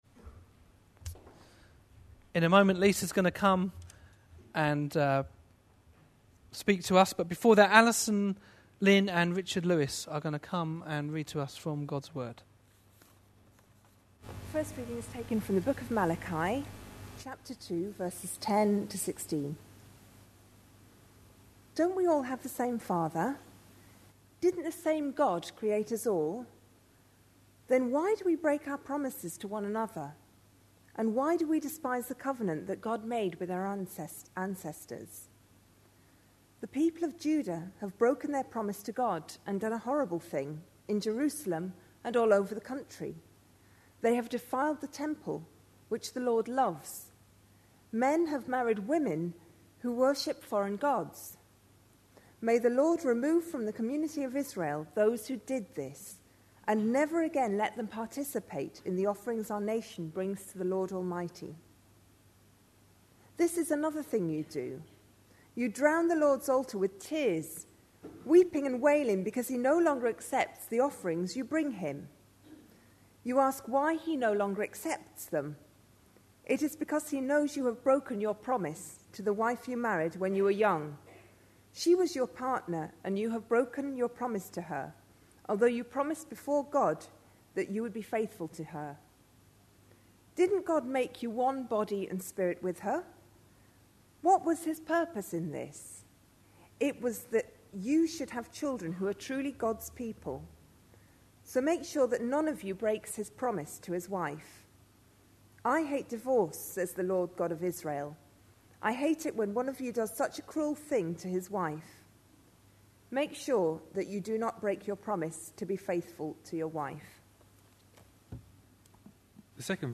A sermon preached on 16th October, 2011, as part of our Malachi (Sunday evenings). series.